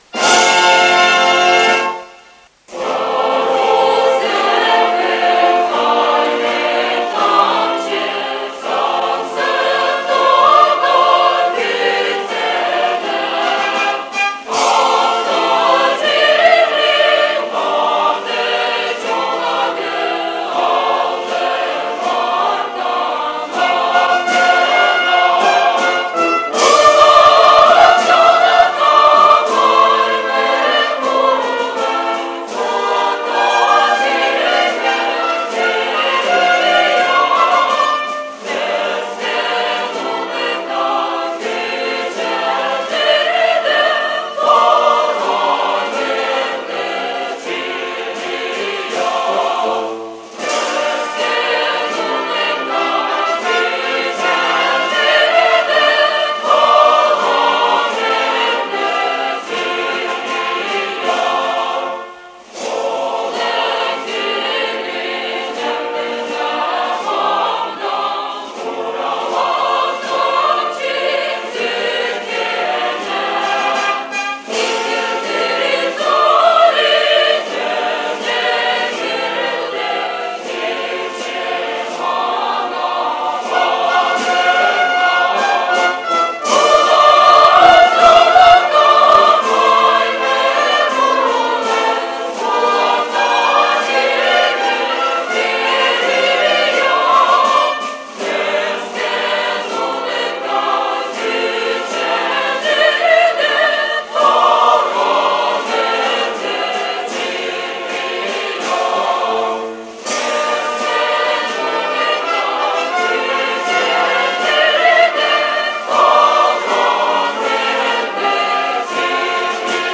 в симфоническом исполнении с хором и национальными мотивами